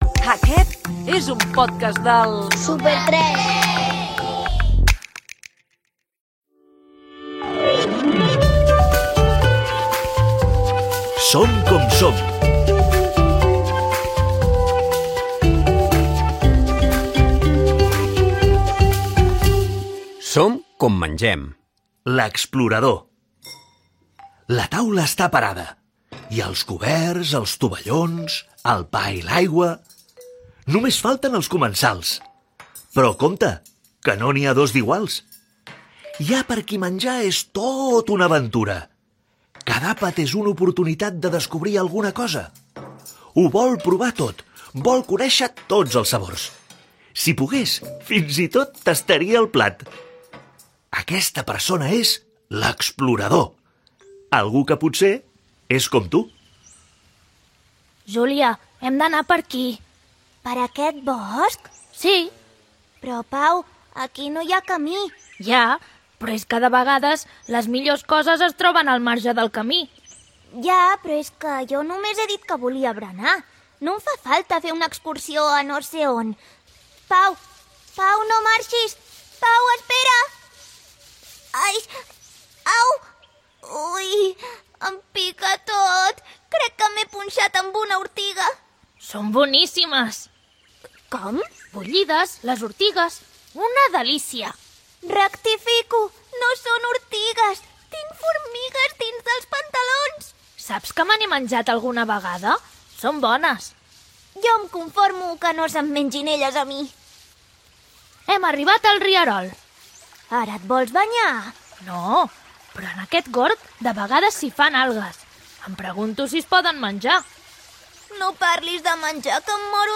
Identificació del Súper 3. Careta del programa, "Som com mengem. L'explorador"
Infantil-juvenil